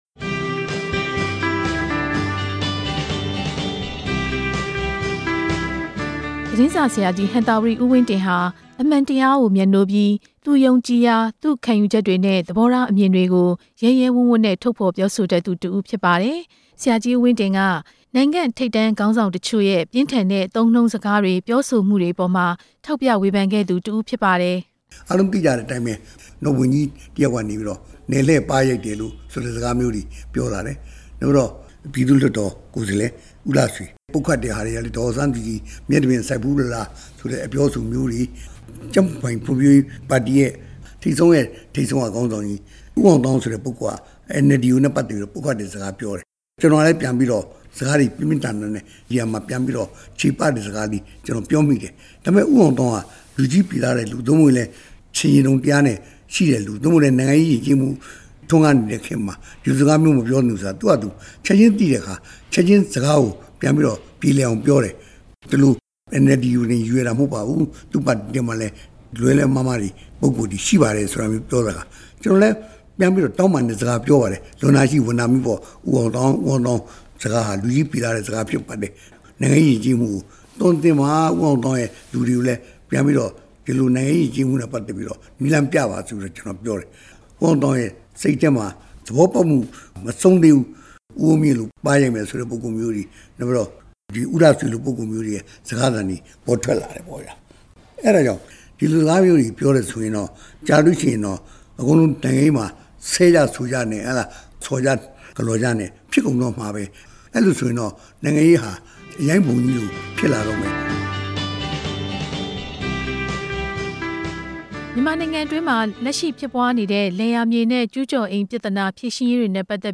ဆရာကြီး ဦးဝင်းတင် မကွယ်လွန်မီက ပြောကြားချက်တချို့ နားထောင်ရန်